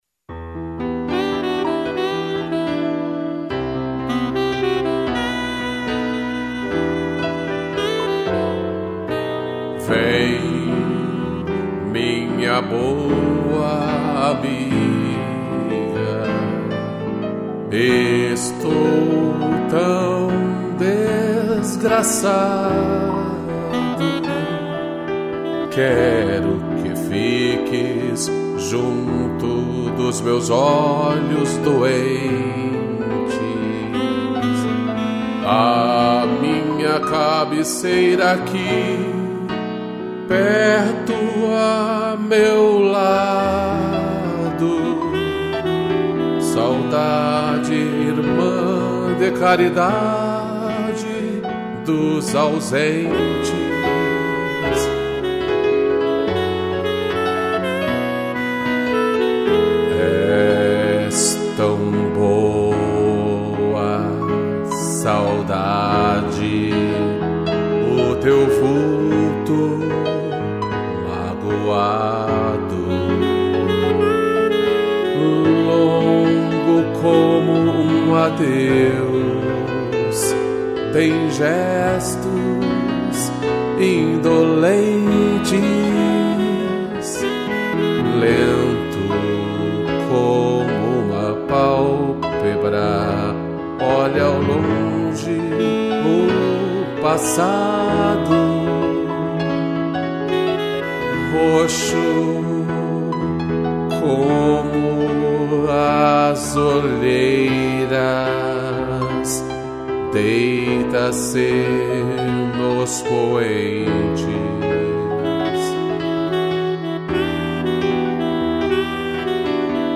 2 pianos e sax